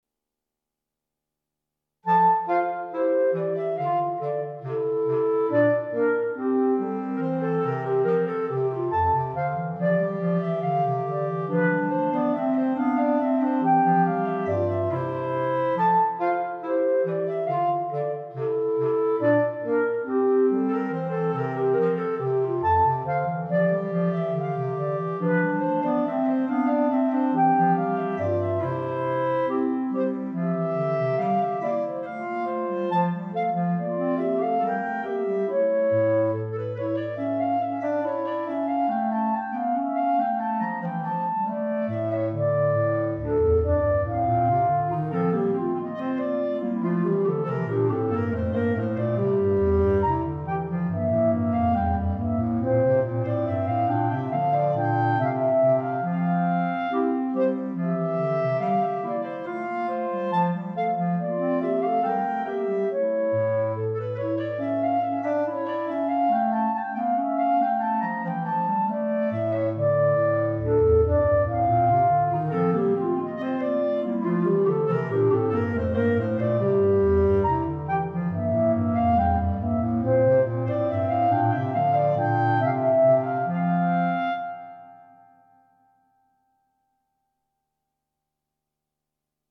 Right click to download Gavotte minus Bass Clarinet